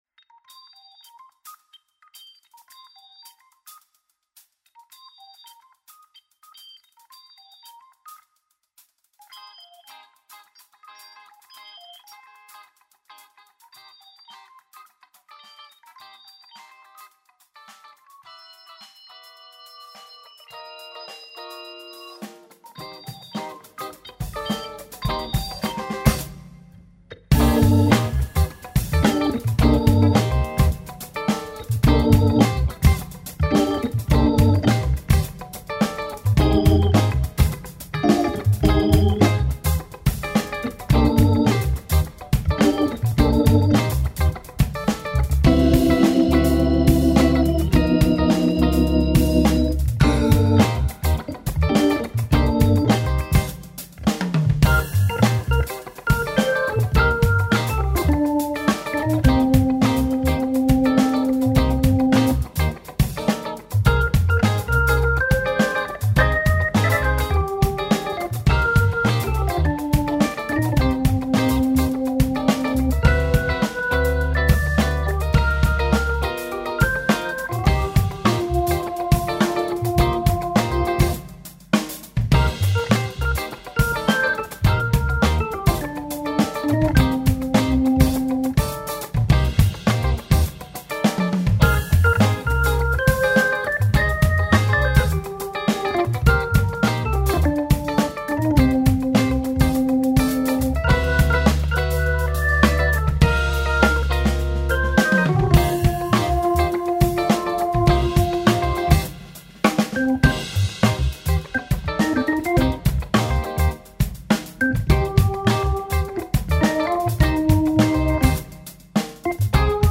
ça groove